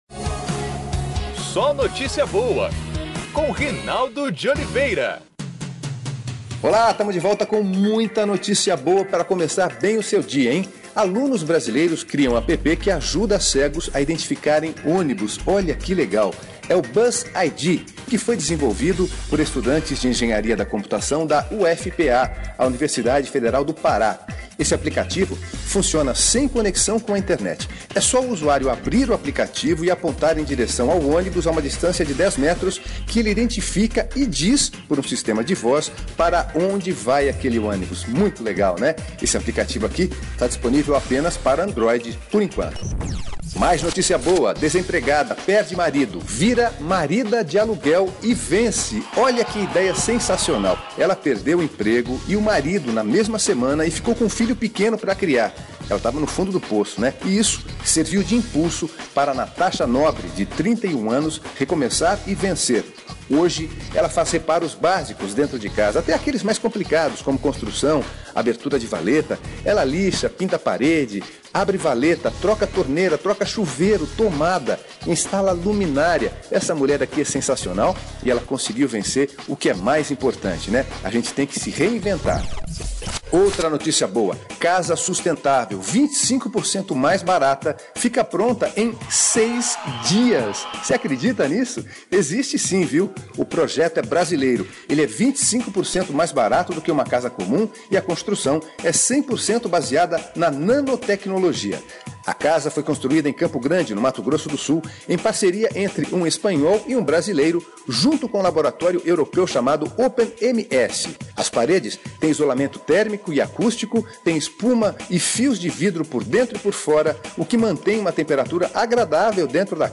É o programa de rádio do SóNotíciaBoa